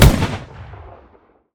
gun-turret-end-2.ogg